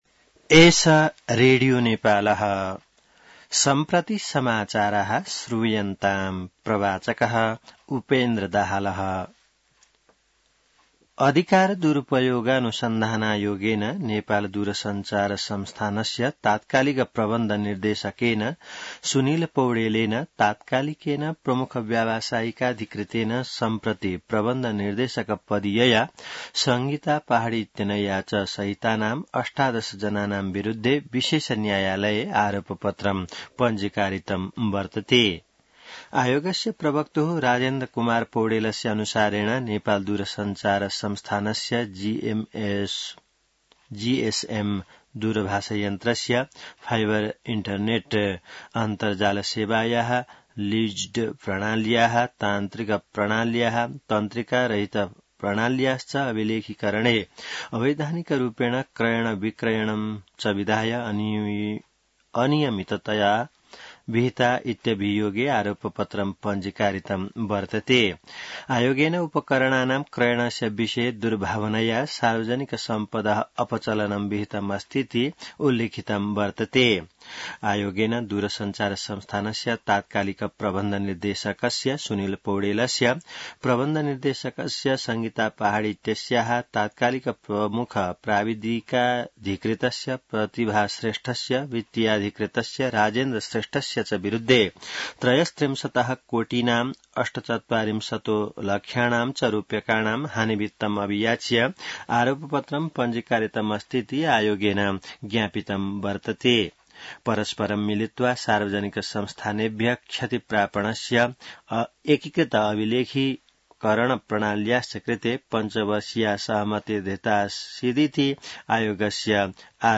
संस्कृत समाचार : २६ जेठ , २०८२